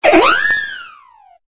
Cri de Farfaduvet dans Pokémon Épée et Bouclier.